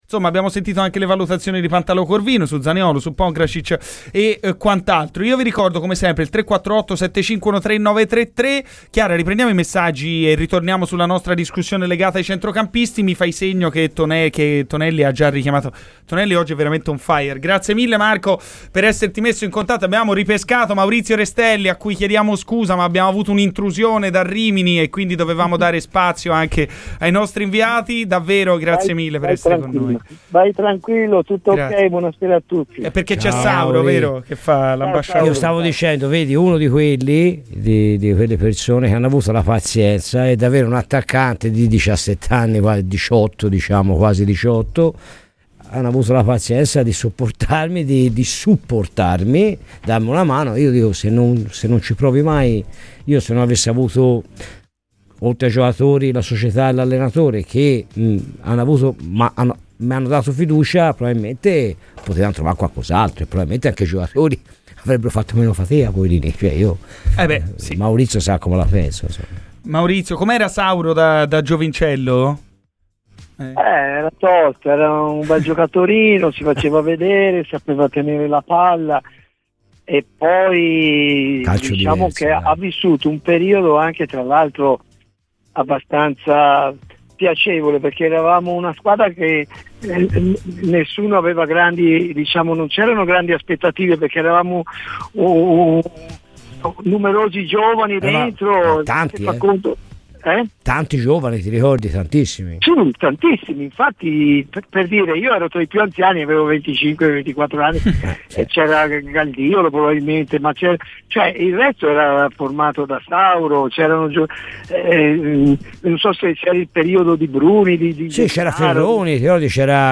Castrovilli ha talento e sa saltare l'uomo e inventare qualcosa, fa la differenza e aiuta tutto il reparto" ASCOLTA L'INTERVISTA COMPLETA DAL PODCAST